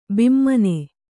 ♪ bimmane